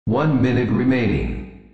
Announcer
OneMinuteRemaining.wav